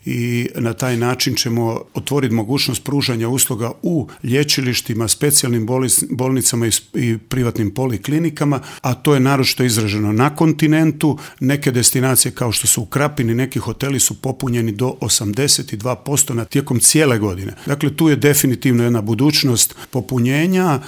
Kako zadržati rast i iduće godine, produljiti sezonu, ali i rješiti problem održivosti turizma te kroničnog nedostatka radne snage u intervjuu Media servisu otkriva resorni ministar Gari Cappelli.